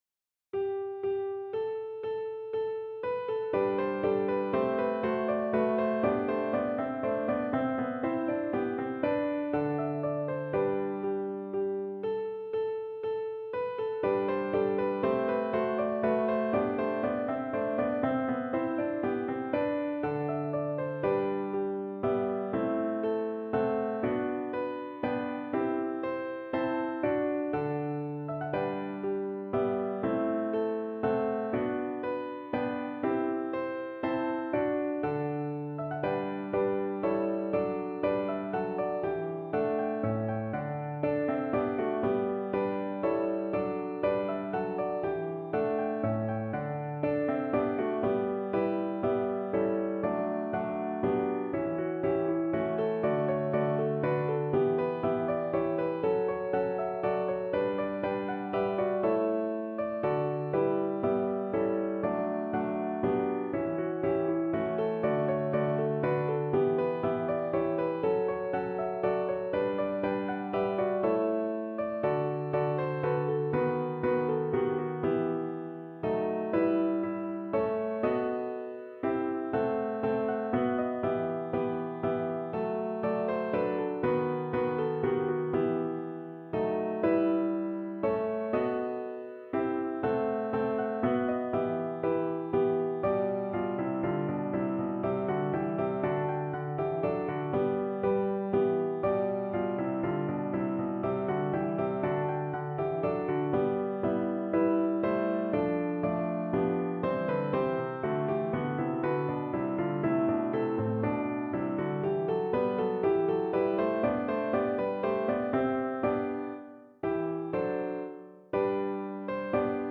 Free Sheet music for Choir (SATB)
Choir  (View more Intermediate Choir Music)
Classical (View more Classical Choir Music)